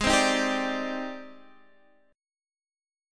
Listen to G#M#11 strummed